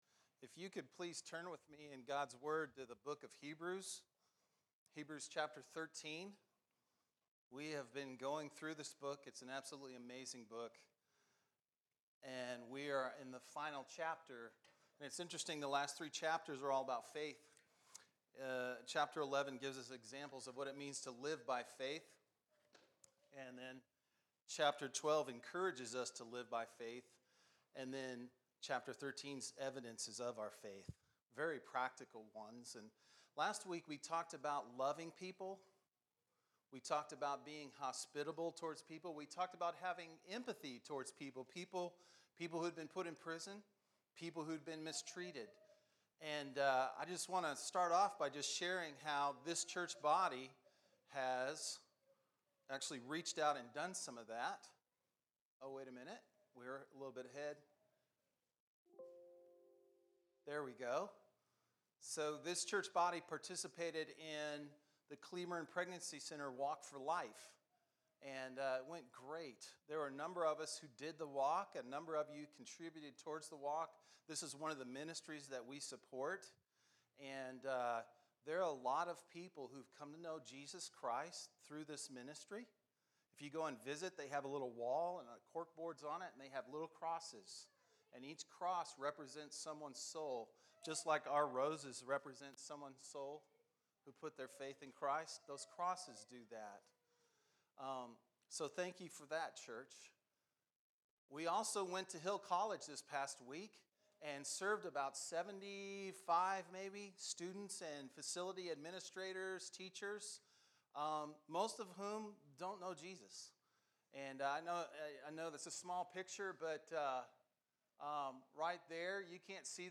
Sermons - Chambers Creek